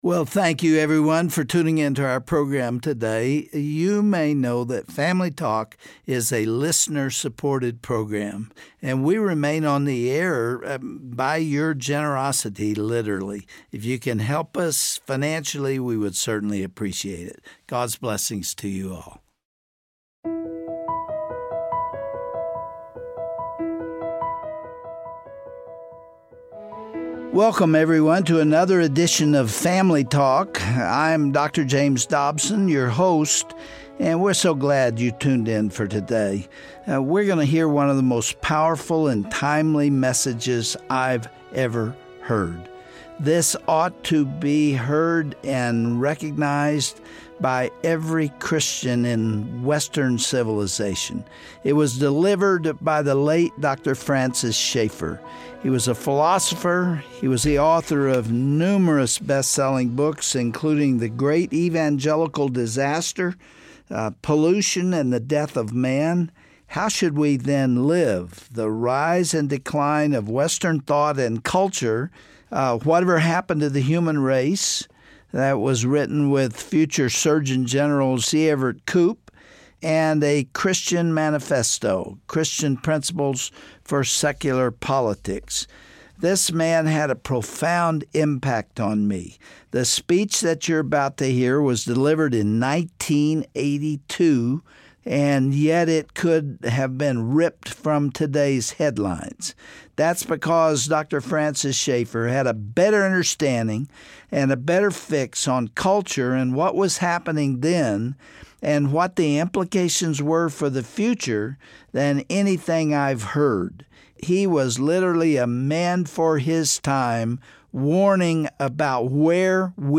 Host Dr. James Dobson
Guest(s): Dr. Francis Schaeffer